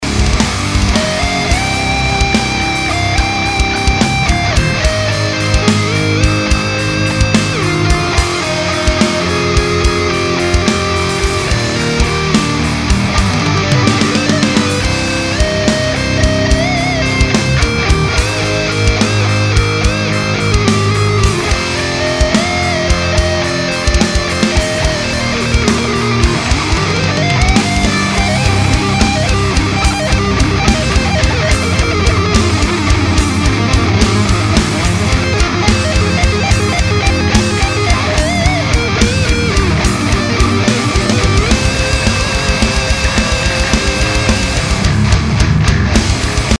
VERY nice, liking that a lot! shits all over my 2 drunk solos.
(the first one still has some fast shit in it though)